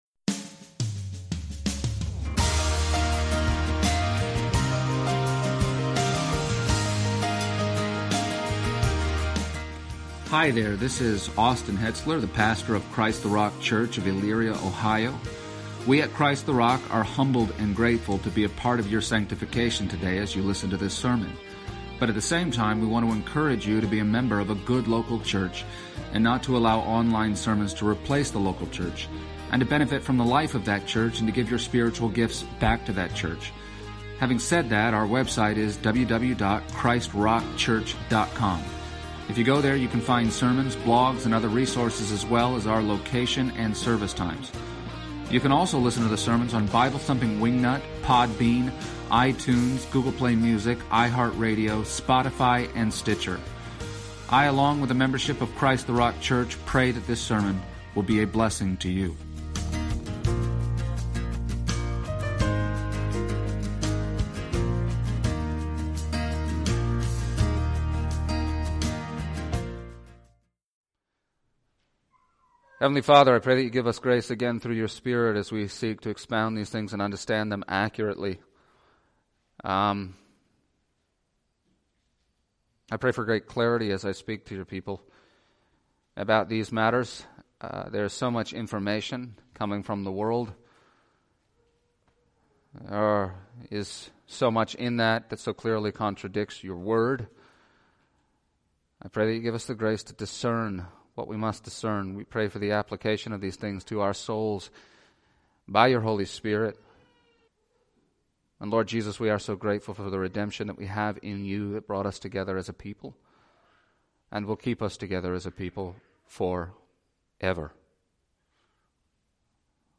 and the Christian Service Type: Sunday Morning %todo_render% « Personal Liberty